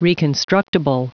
Prononciation du mot : reconstructible
reconstructible.wav